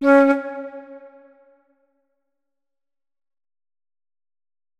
328d67128d Divergent / mods / Hideout Furniture / gamedata / sounds / interface / keyboard / flute / notes-37.ogg 44 KiB (Stored with Git LFS) Raw History